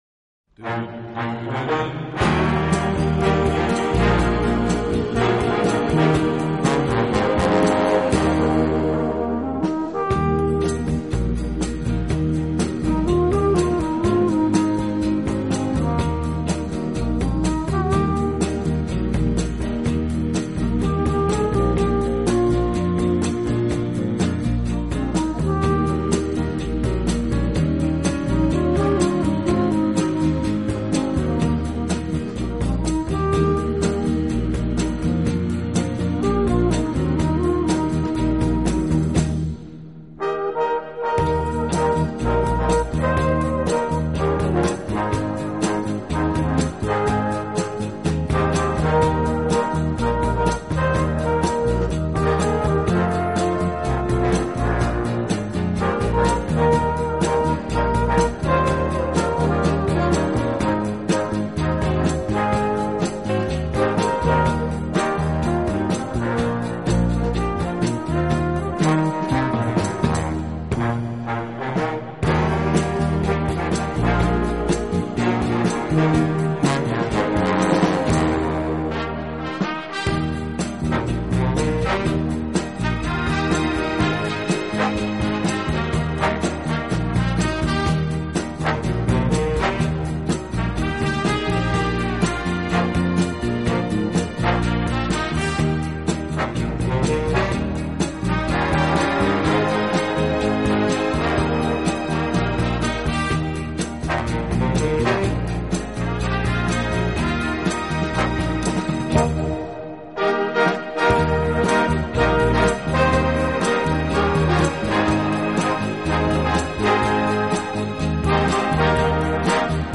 小号的音色，让他演奏主旋律，而由弦乐器予以衬托铺垫，音乐风格迷人柔情，声情并
温情、柔软、浪漫是他的特色，也是他与德国众艺术家不同的地方。